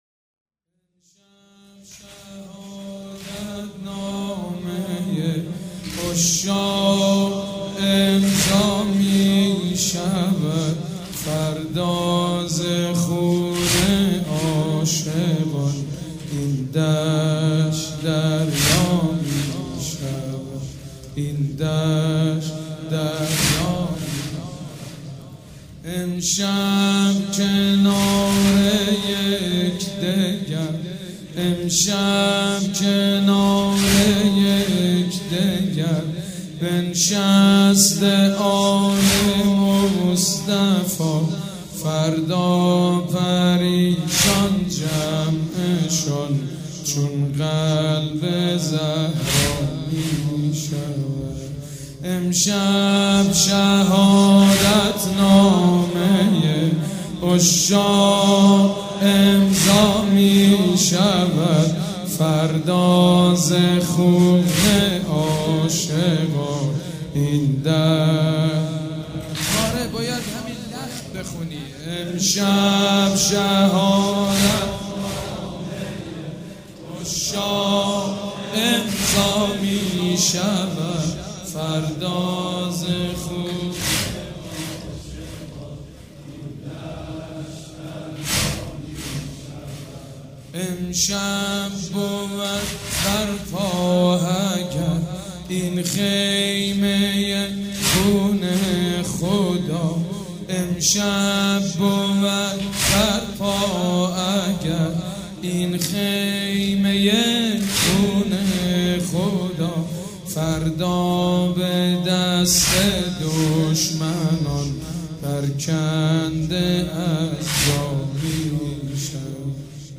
شب دهم محرم الحرام‌
مراسم عزاداری شب عاشورا